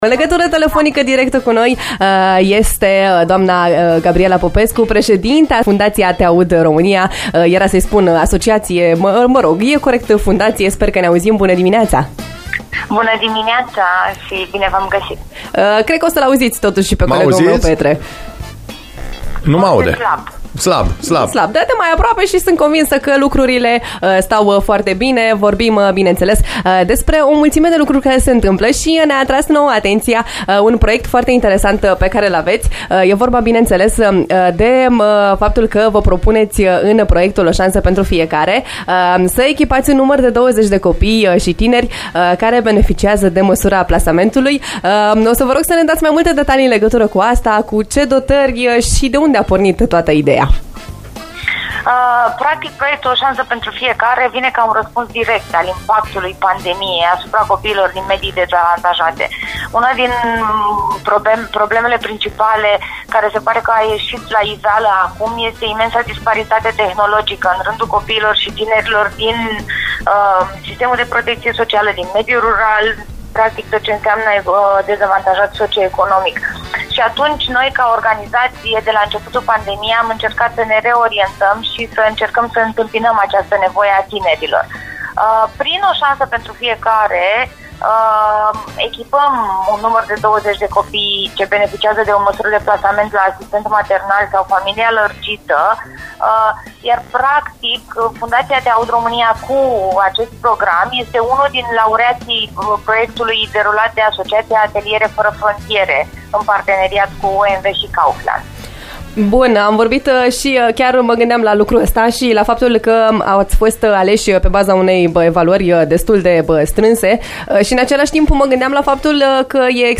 Interviul, integral, aici: